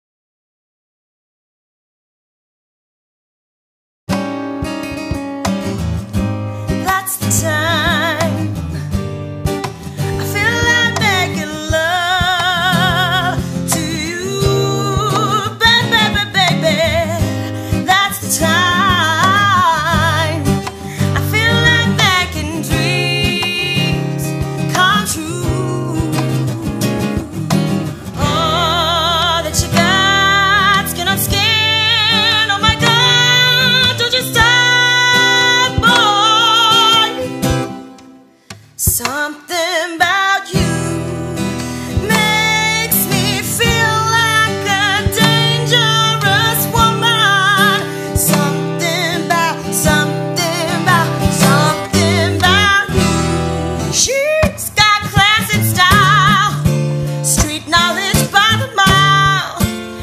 Vocals | DJ | Percussion | Sax